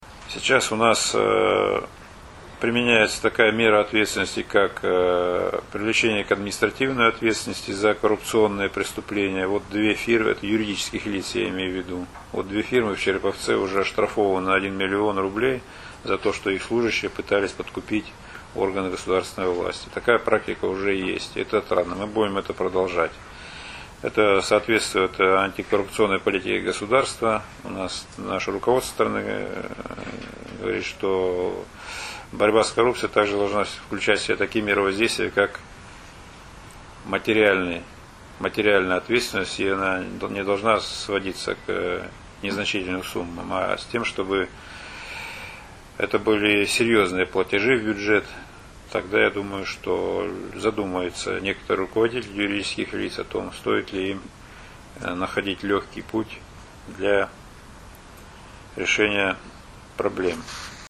Об этом в четверг, 19 июля, на пресс-конференции заявил прокурор области Сергей Хлопушин. По его словам, количество преступлений, связанных с коррупцией, в первом полугодии 2012 года в регионе выросло на треть, сообщает ИА «СеверИнформ — Новости Вологодской области».